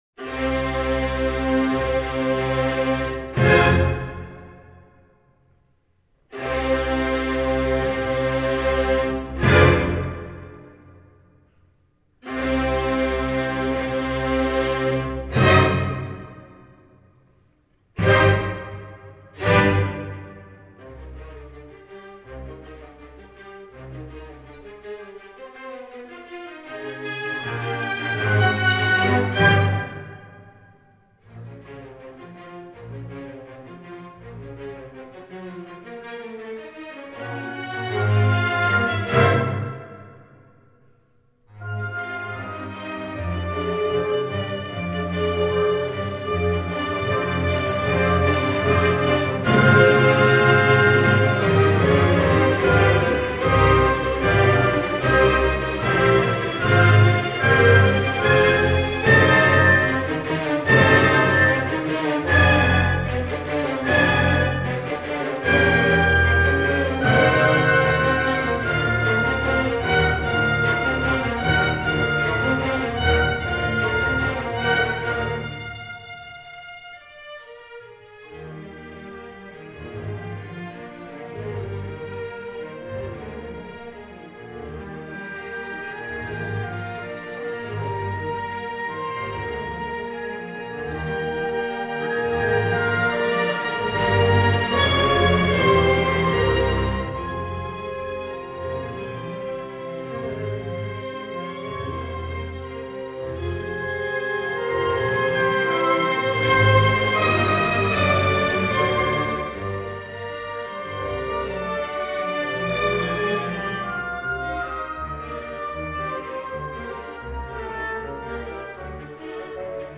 Orchestra  (View more Intermediate Orchestra Music)
Classical (View more Classical Orchestra Music)
Audio: Czech National Symphony Orchestra